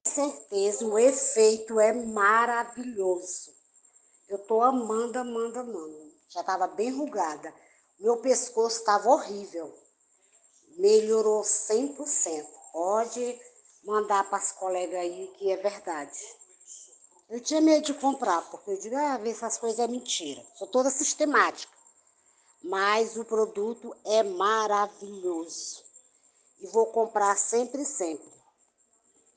Depoimentos em Aúdio de pessoas que usa o Trans Resveratrol Gota: